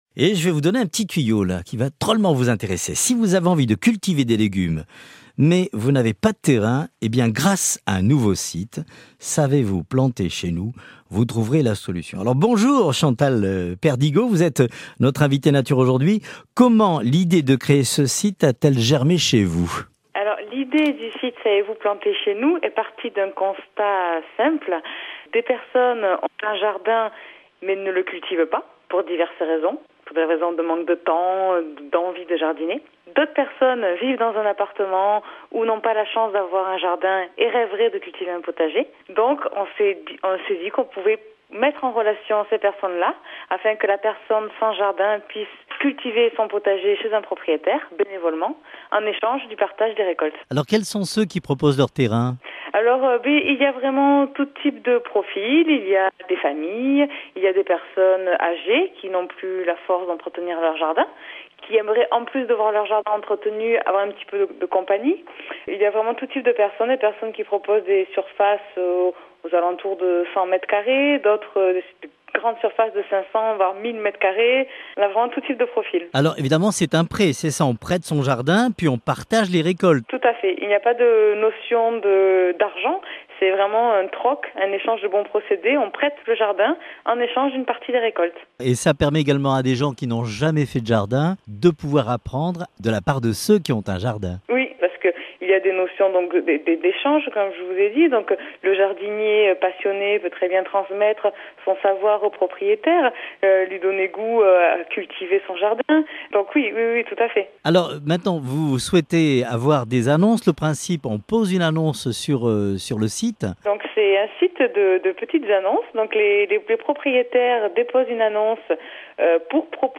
Chaque weekend, un invité « Nature » au micro de France Bleu.